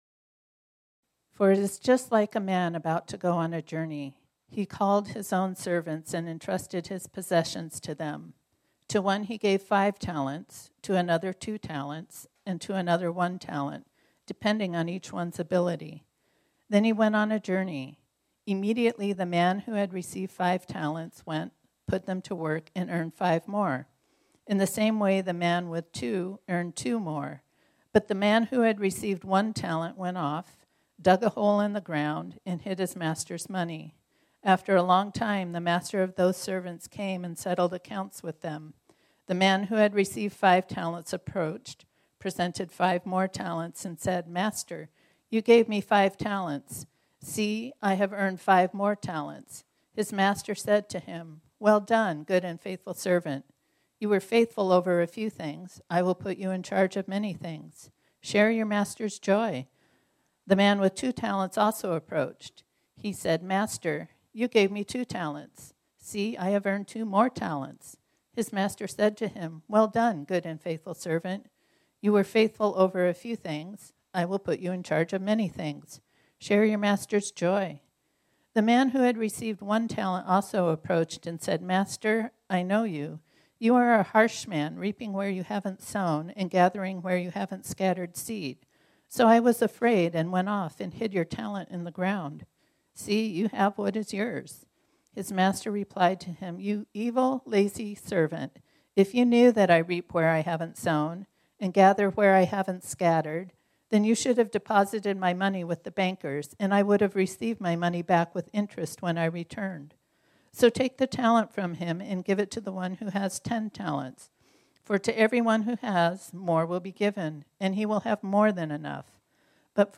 This sermon was originally preached on Sunday, October 27, 2024.